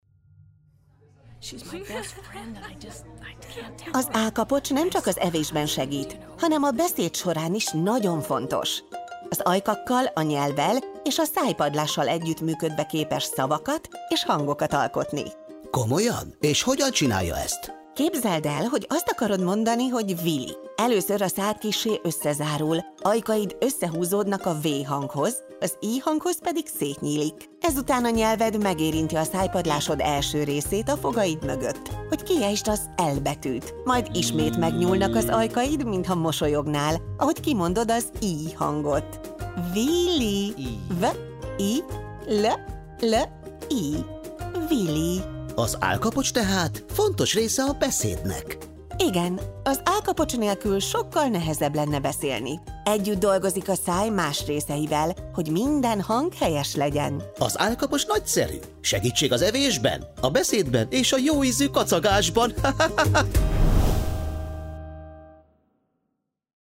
A sorozat II. lapszámához tartozó játék sztetoszkóppal meghallgatható bizonyos testrészek hangja, valamint lejátszható egy rövid párbeszéd a testrésszel kapcsolatos érdekességekről.